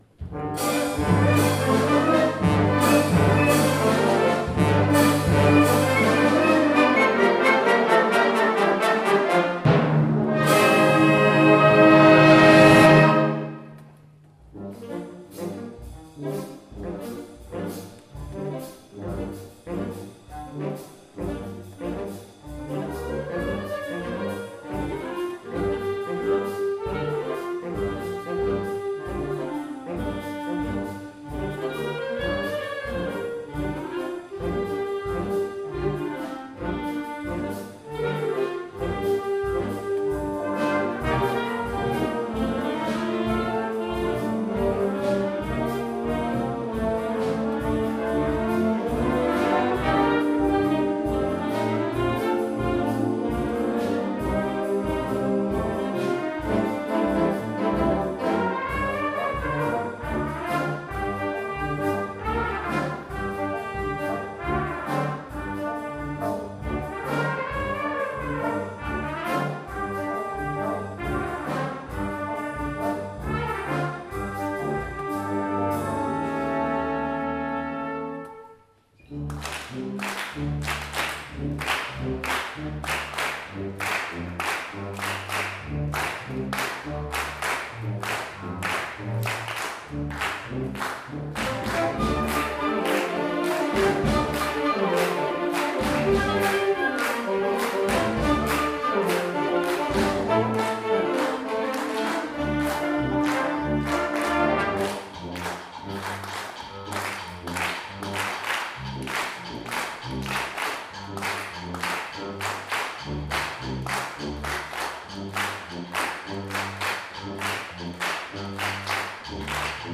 Concert de Printemps 2 avril 2023
1ère partie Orchestre d'Harmonie de Montigny-lès-Metz